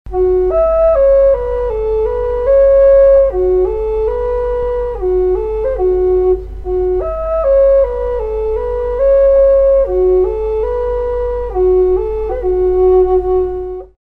Пимак F#
Пимак F# Тональность: F#
Прост в исполнении, но имеет вполне достойное звучание. Изготовлен из испанского кедра.